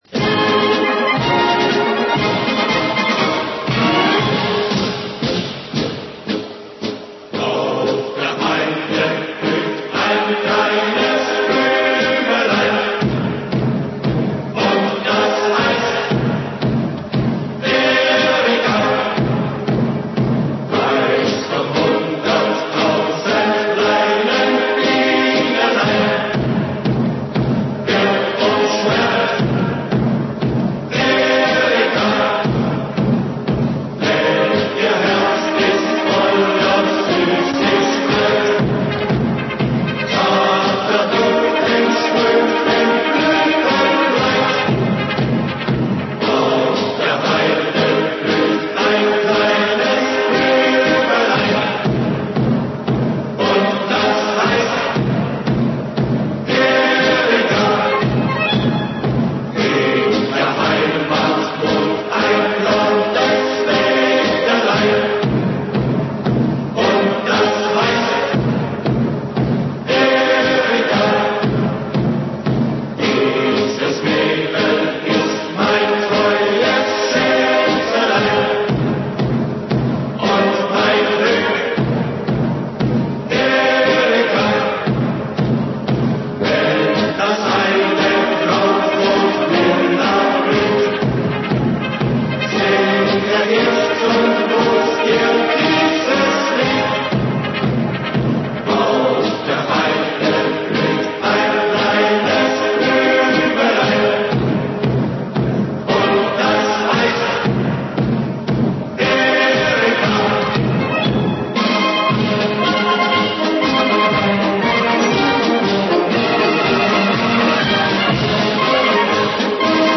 Слушаем оригинальный вариант «Эрики». Звучат первые два куплета:
2). Музыка полностью отражает эту особенность текста. Там, где ломается ритм, она замолкает, и в наступившей паузе отстукивает только метроном: 120 шагов в минуту.